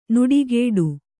♪ nuḍigēḍu